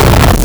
Player_Glitch [17].wav